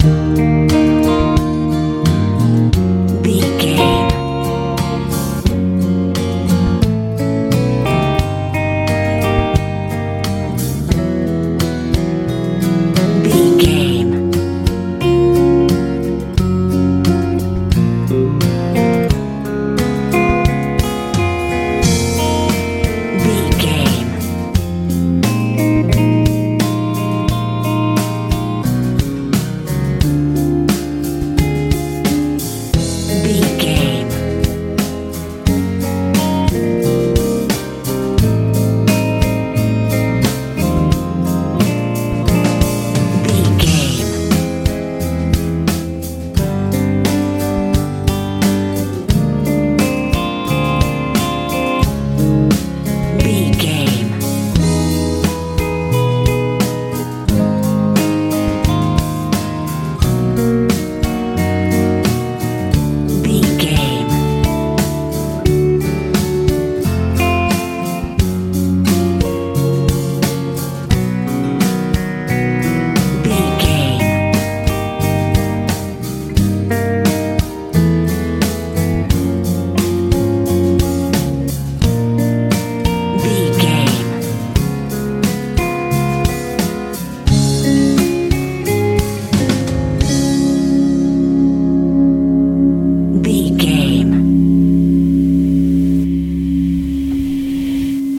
dreamy pop feel
Ionian/Major
E♭
light
mellow
electric guitar
acoustic guitar
bass guitar
drums
soothing
soft
smooth
dreamy